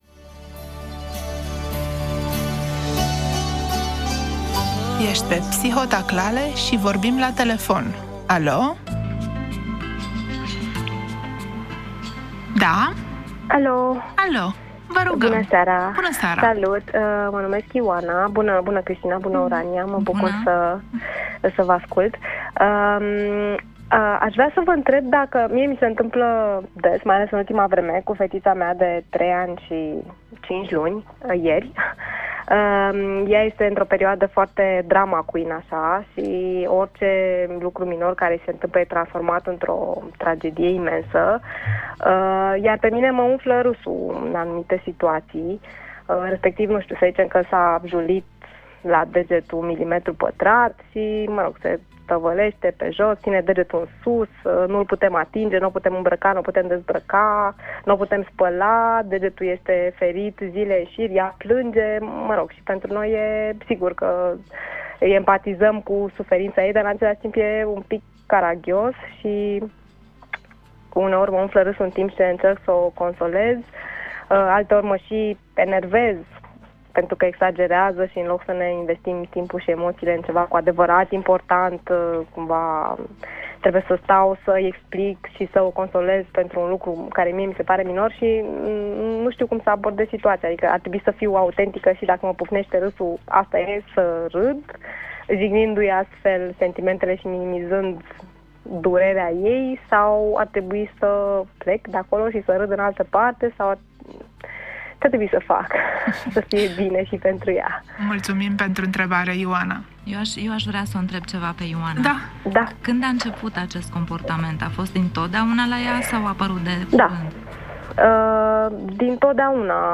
Click play mai jos pentru a auzi întrebarea și răspunsul meu.